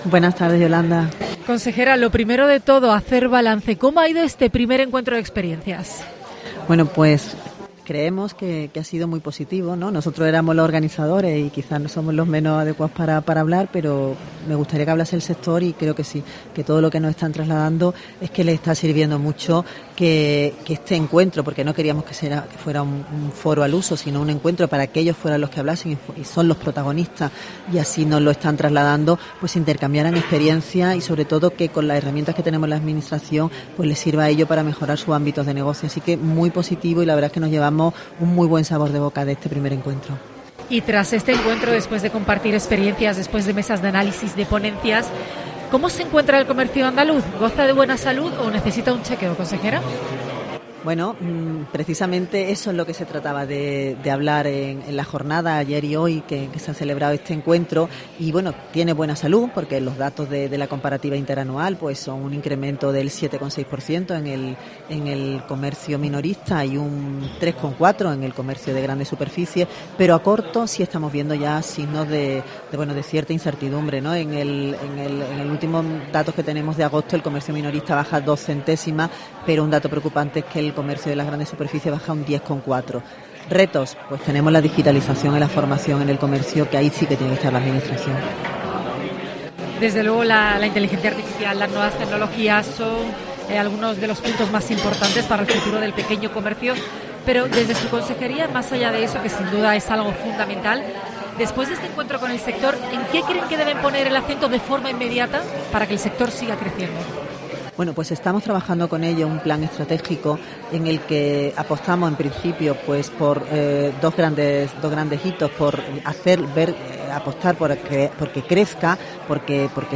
La Consejera Rocío Blanco ha pasado por los micrófonos de COPE Andalucía para hacer balance de estas horas.